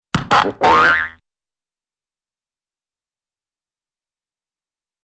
tumble and sound effects